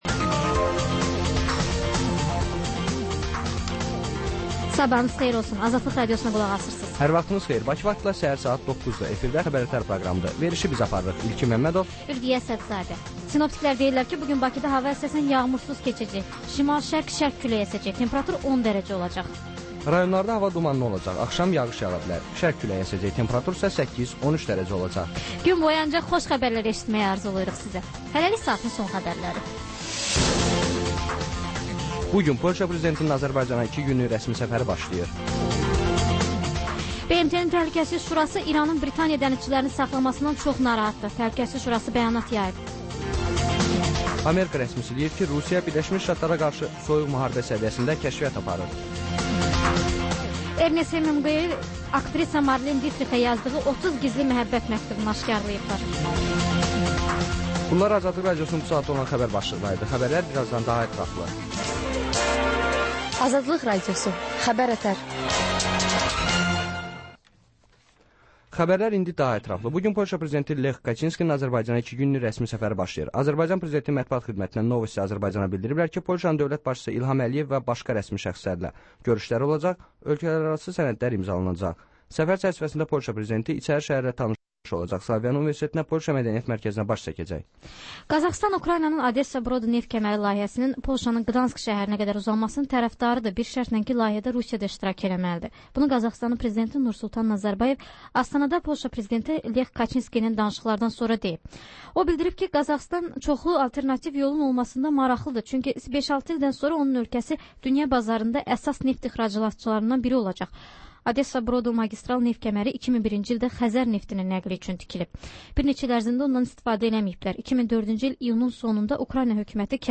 Xəbər-ətər: xəbərlər, müsahibələr, sonra 14-24: Gənclər üçün xüsusi veriliş